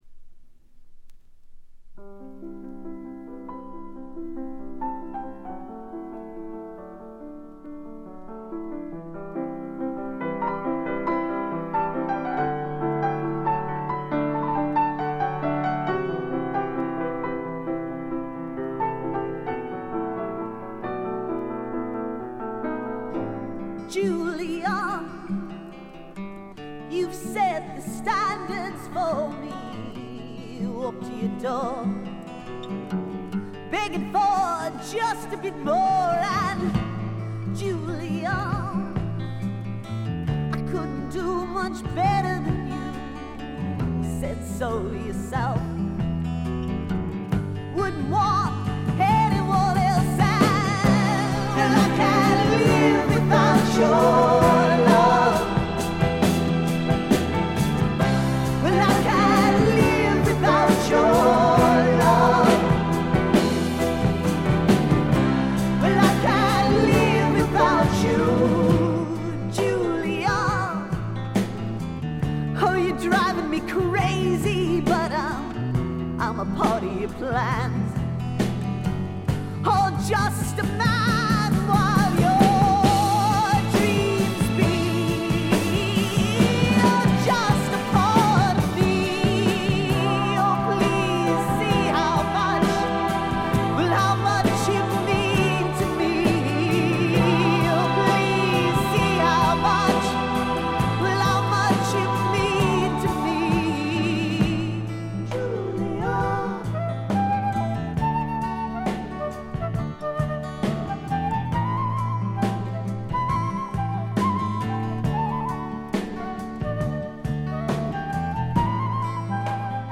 ほとんどノイズ感無し。
米国産ハード・プログレッシヴ・ロックの名盤。
忘れてならいのは曲がポップで哀愁味もあってとても良くできていること。
試聴曲は現品からの取り込み音源です。